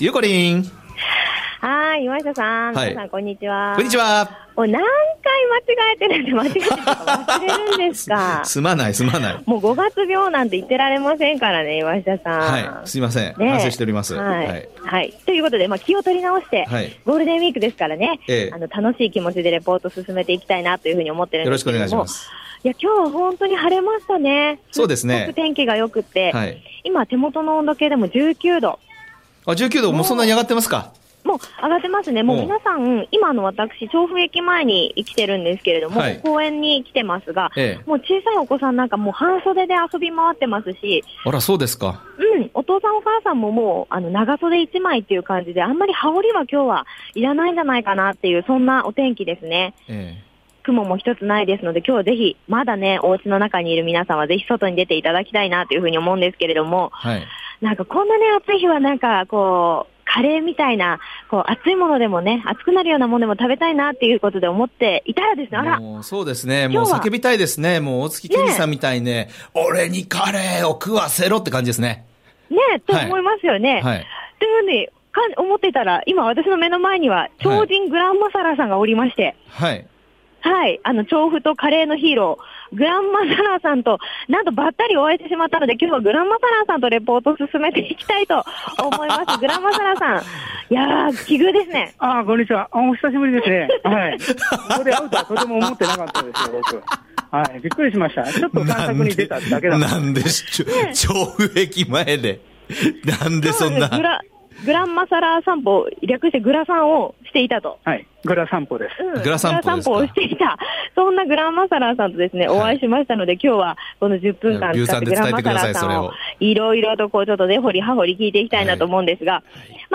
★びゅーサン 街角レポート